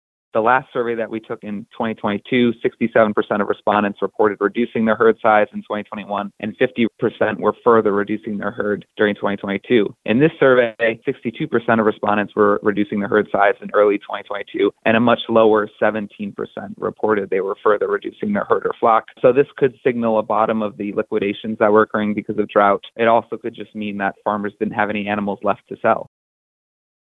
Audio provided as a service to farm broadcasters by the American Farm Bureau Federation